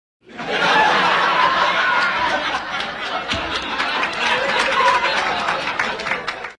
Laugh Track